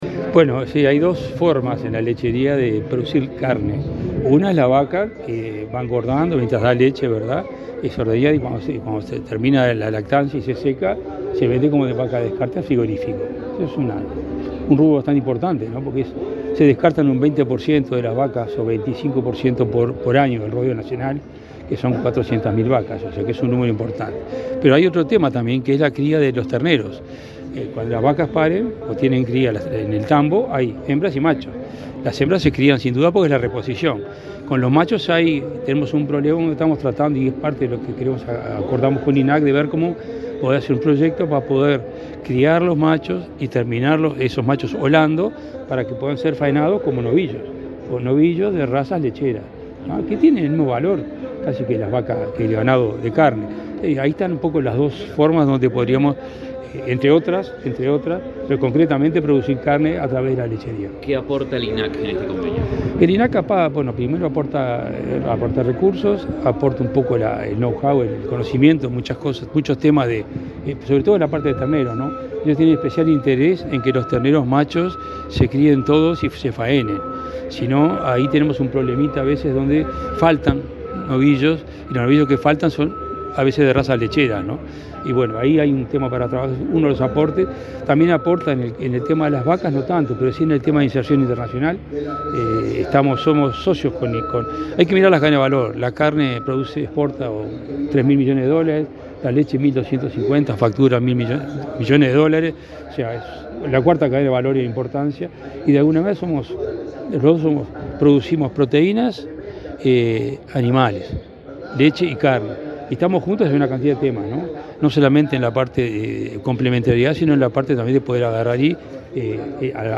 Declaraciones del presidente de Inale, Juan Daniel Vago
Este miércoles 13 en la Expo Prado, el presidente del Instituto Nacional de la Leche (Inale), Juan Daniel Vago, firmó un convenio de cooperación con